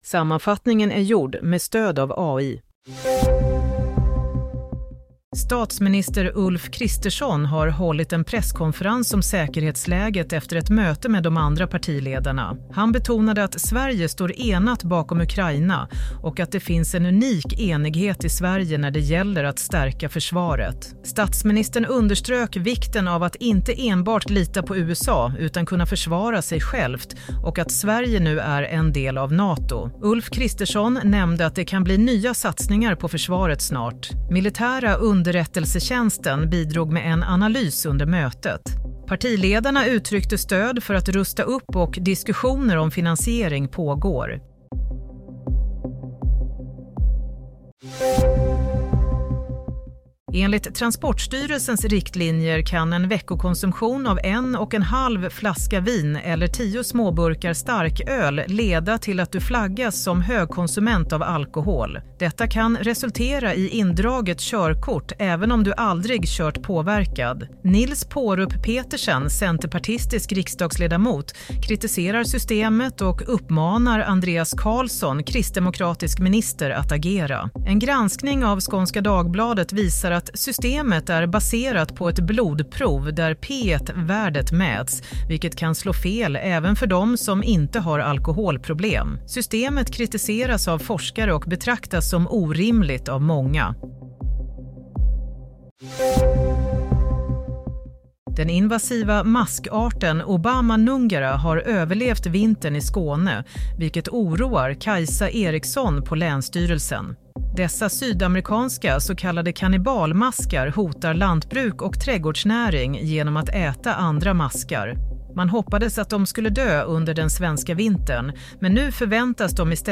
Nyhetssammanfattning – 7 mars 22:00